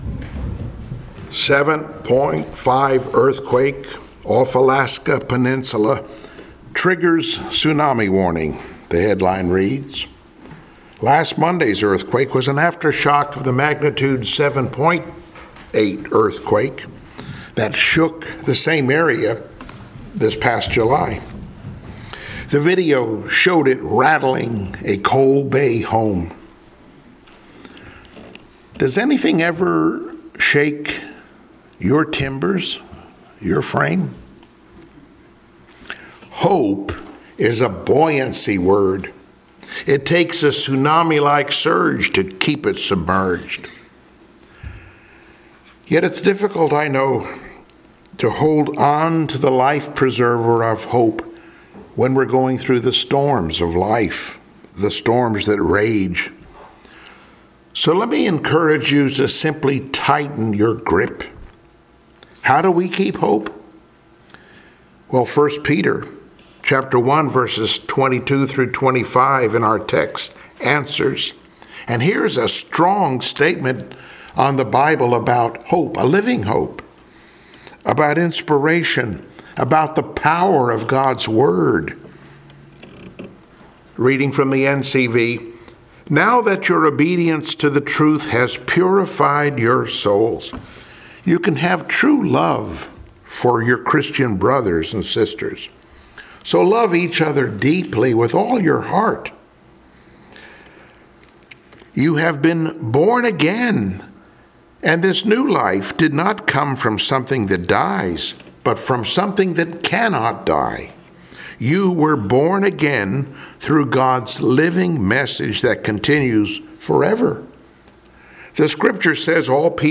Passage: 1 Peter 1:22-25; 2:1-3 Service Type: Sunday Morning Worship Download Files Notes Bulletin « “Enjoy The Glory Now” “Becoming Living Stones” »